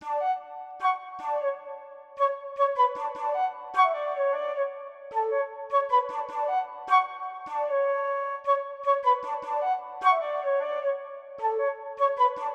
Gold_Flute.wav